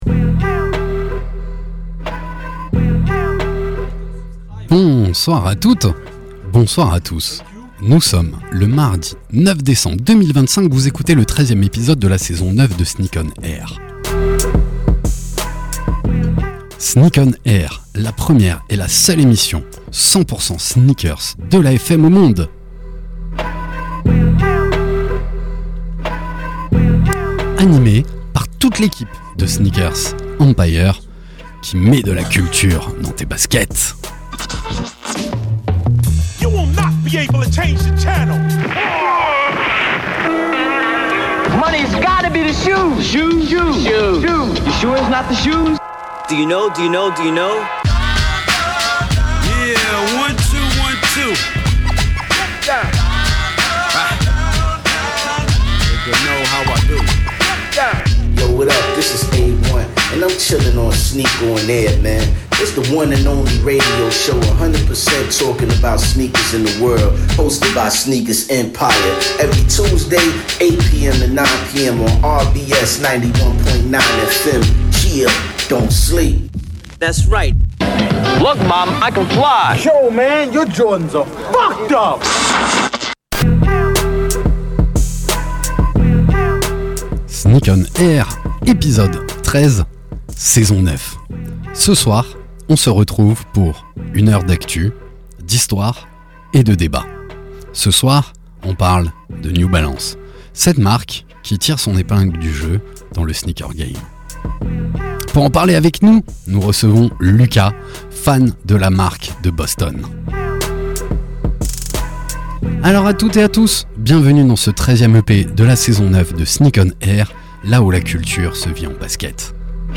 Sneak ON AIR, la première et la seule émission de radio 100% sneakers au monde !!! sur la radio RBS tous les mardis de 20h à 21h.
Actu sneakers, invités, SANA, talk.